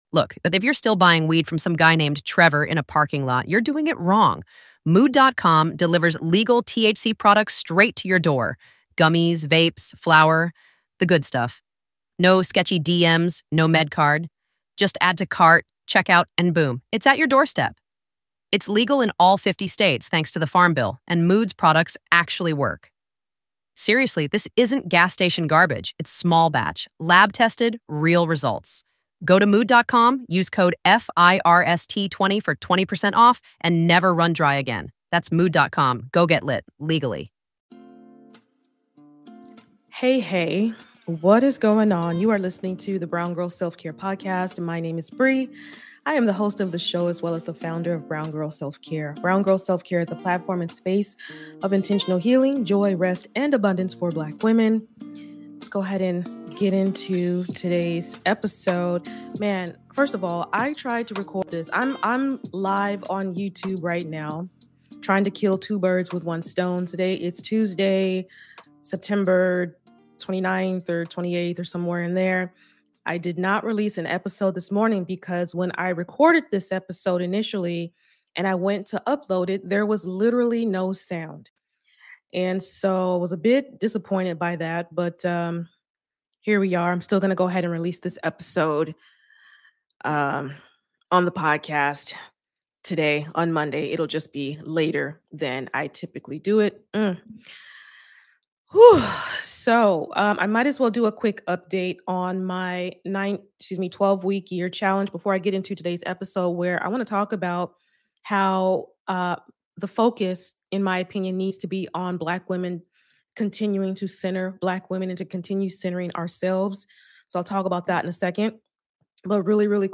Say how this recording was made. So I decided to hop onto Youtube Live on Monday morning and record it there.